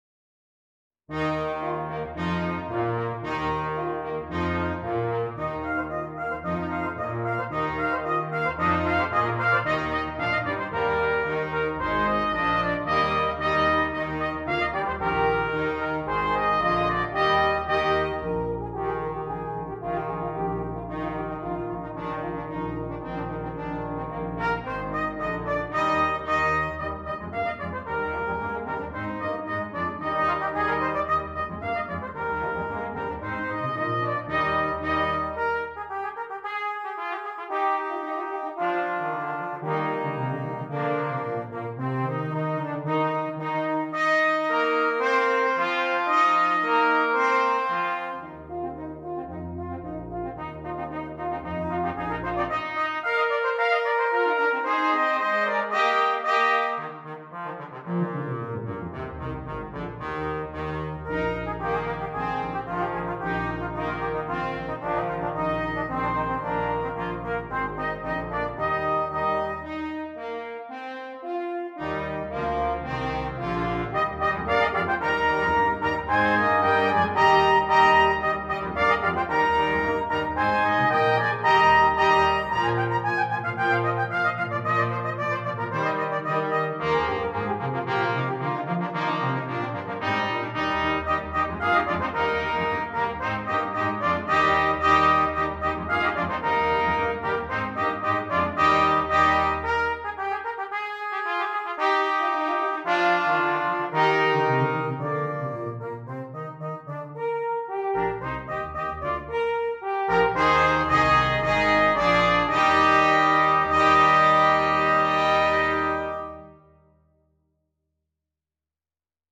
Christmas
Brass Quintet
Traditional